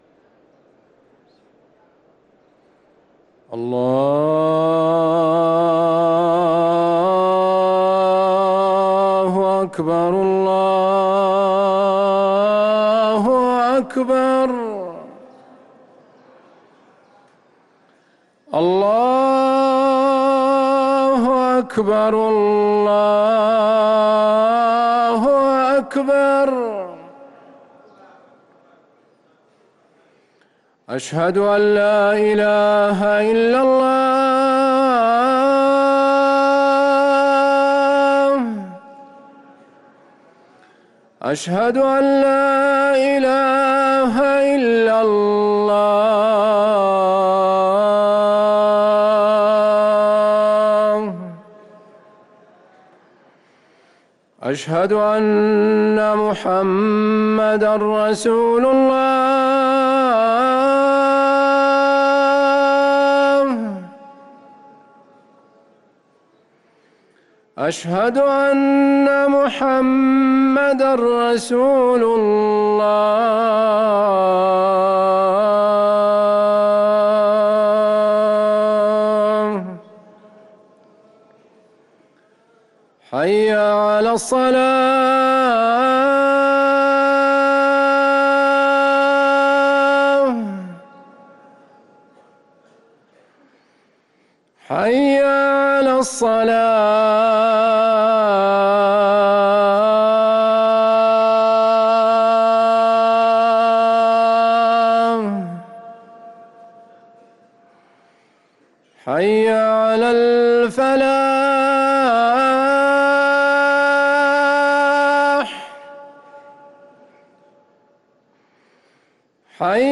أذان العصر